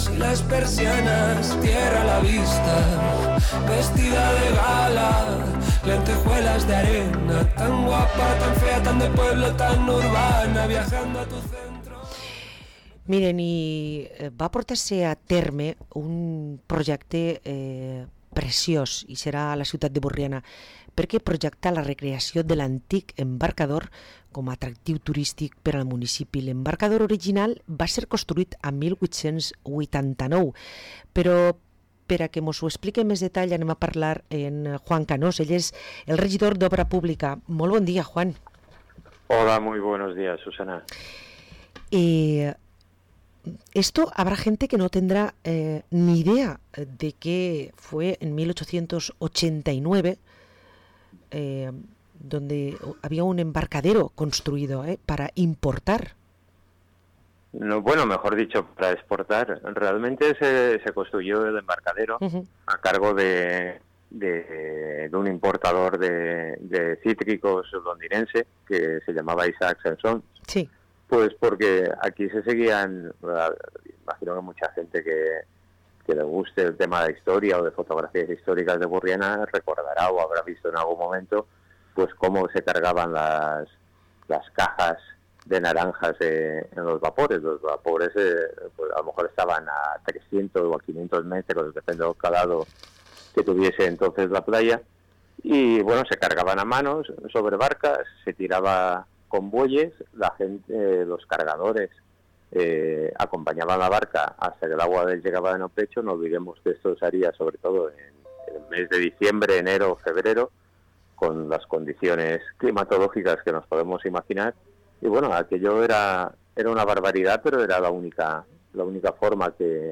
Parlem amb Juan Canós, regidor d´Obra Pública, a l´Ajuntament de Burriana